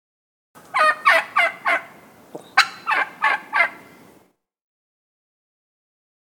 Turkey Gobble And Squawk Efecto de Sonido Descargar
Turkey Gobble And Squawk Botón de Sonido